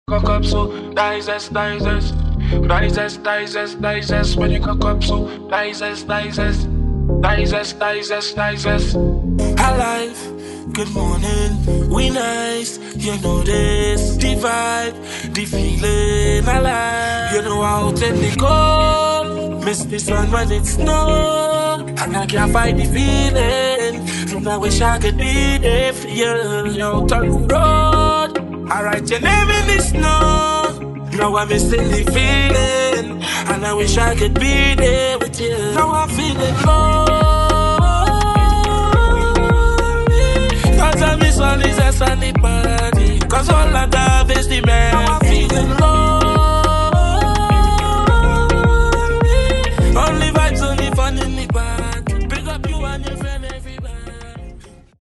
THIS IS A SINGLE TRACK REMIX (STR).
Soca